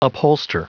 Prononciation du mot upholster en anglais (fichier audio)
Prononciation du mot : upholster